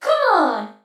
8 bits Elements
Voices Expressions Demo
ComeOn.wav